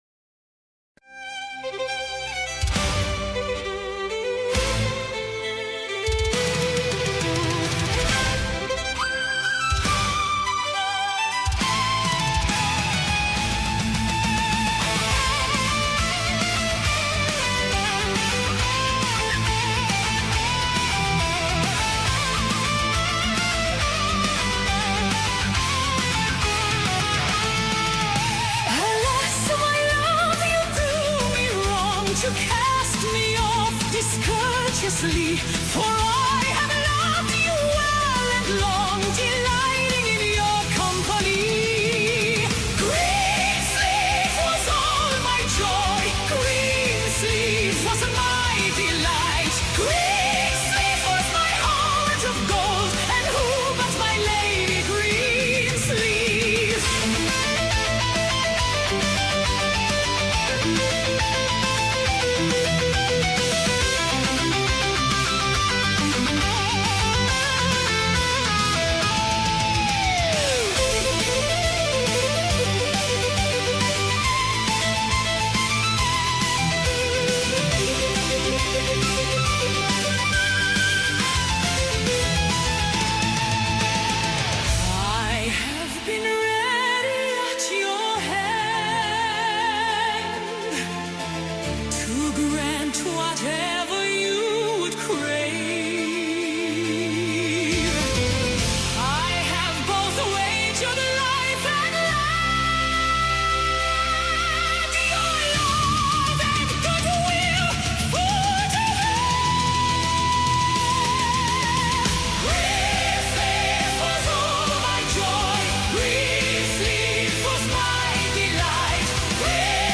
Great song thanks and the redhead can sing :)